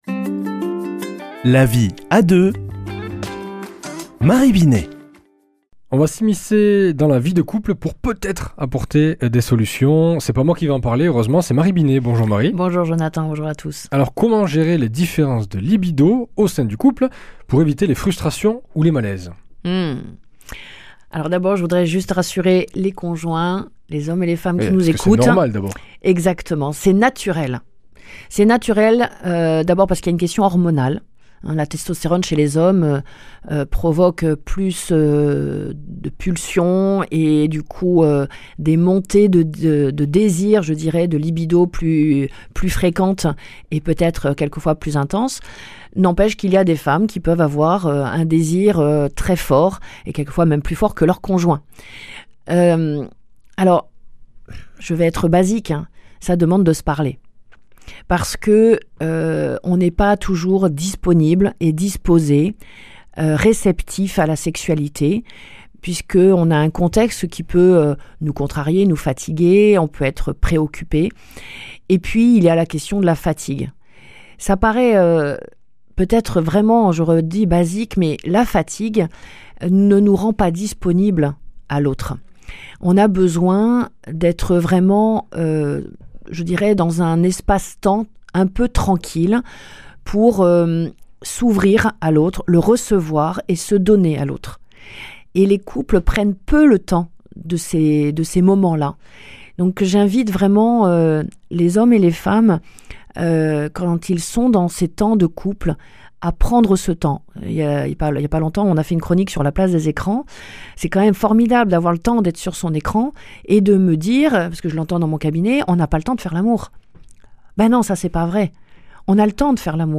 mardi 18 mars 2025 Chronique La vie à deux Durée 4 min